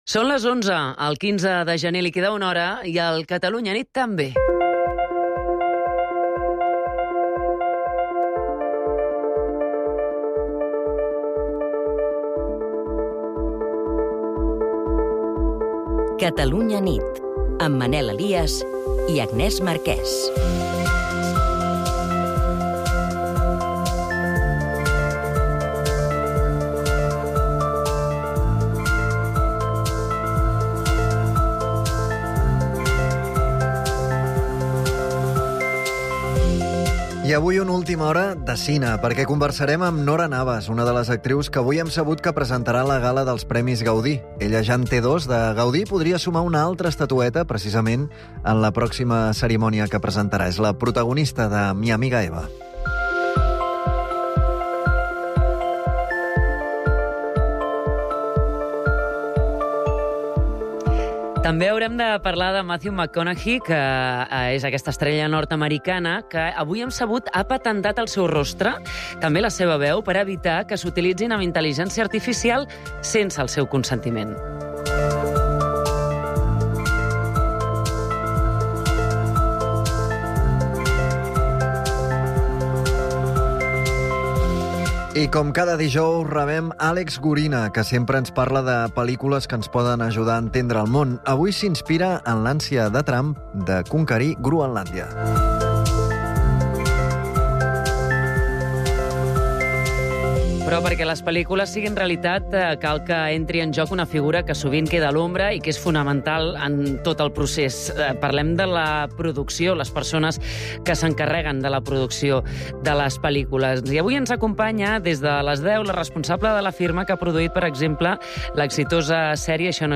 Entrevistem l'actriu Nora Navas, que serà una de les cinc presentadores de la pròxima gala dels Gaudí, on també està nominada pel seu paper a "Mi amiga Eva".